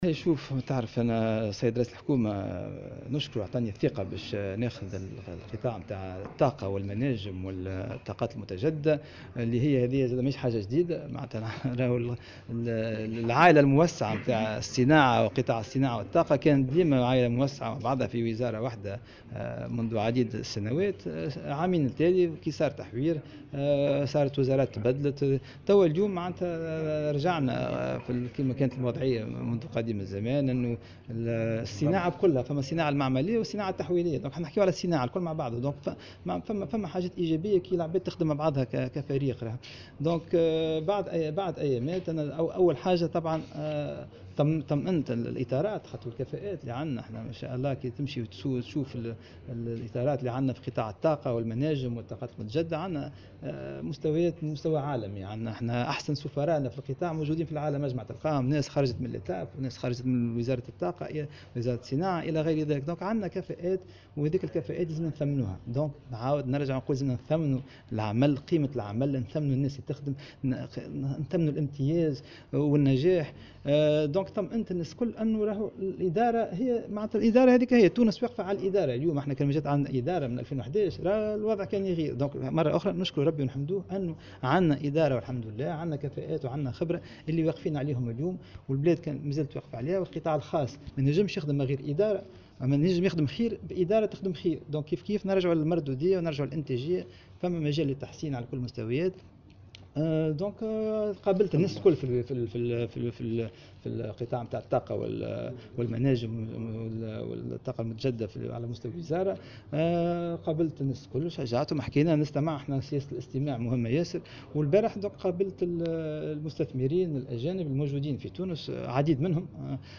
وأكد في تصريح لمراسل "الجوهرة اف أم" على هامش زيارة ميدانية قام بها اليوم الخميس إلى المنستير، أن الإدارة مستمرة باستمرارية الدولة بغض النظر عن اي تغيير بفضل كفاءاتها مشددا على أهمية مردوديتها لفائدة الاستثمار.